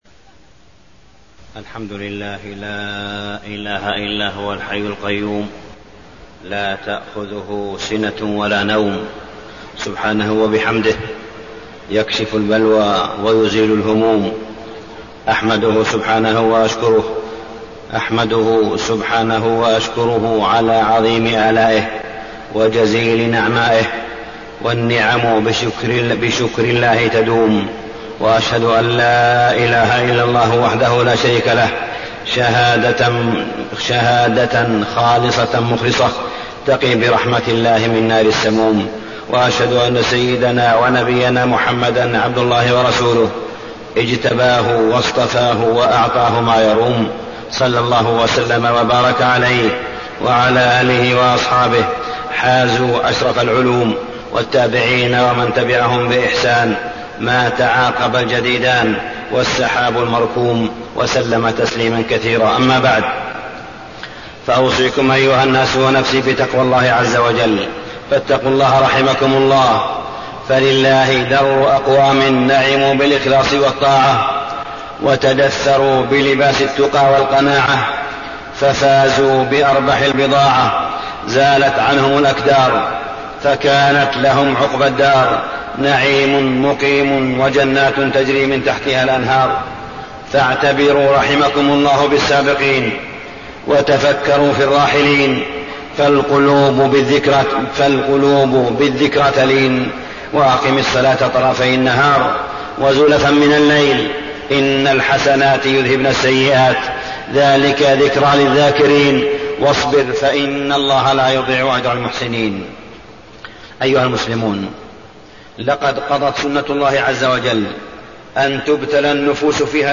تاريخ النشر ٢٧ جمادى الأولى ١٤٣٠ هـ المكان: المسجد الحرام الشيخ: معالي الشيخ أ.د. صالح بن عبدالله بن حميد معالي الشيخ أ.د. صالح بن عبدالله بن حميد ضرورة الإعتبار بالحوادث والكوارث